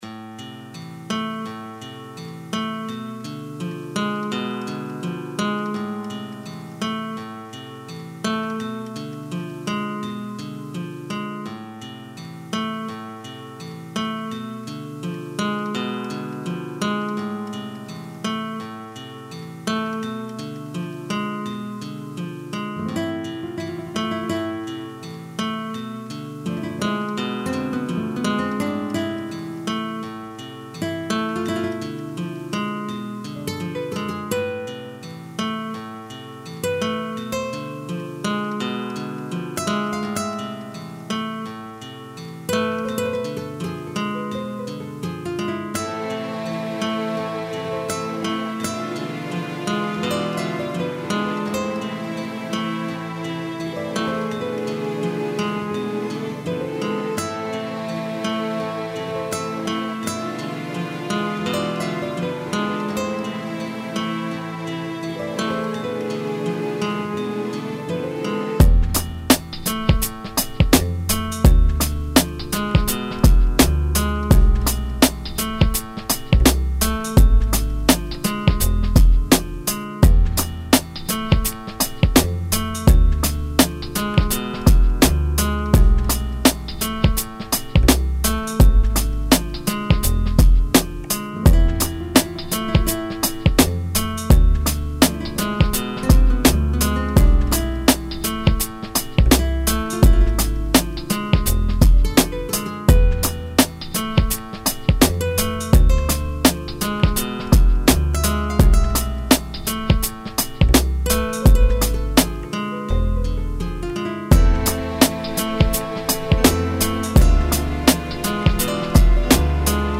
Dirty Drama Pop instrumental